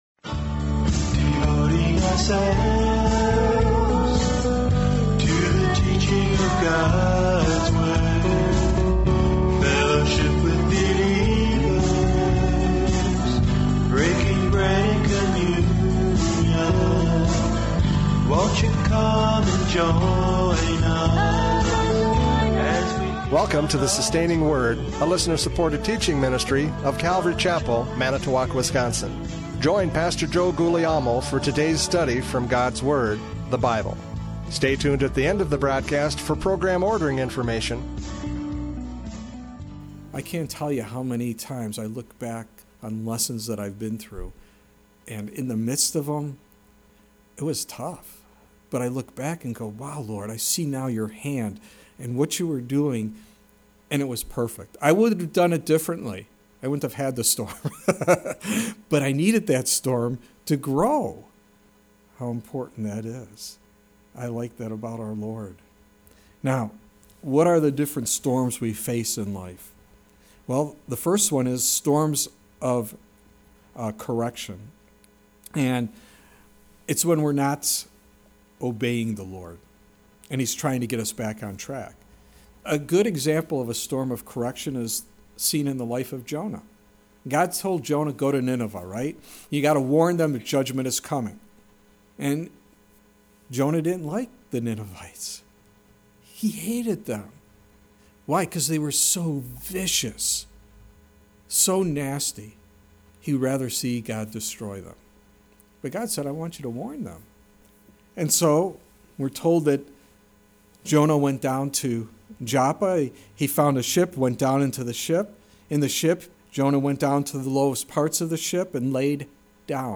John 6:15-21 Service Type: Radio Programs « John 6:15-21 The Eye of the Storm!